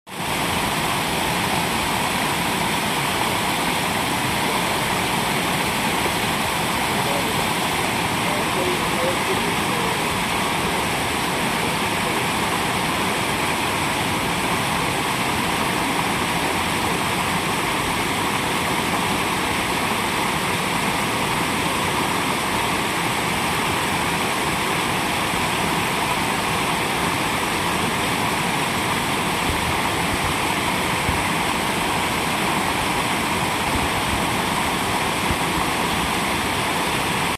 We found this Hammerkop at sound effects free download
We found this Hammerkop at his usual fishing spot on the Sand River, turn up the volume and listen to the river flowing.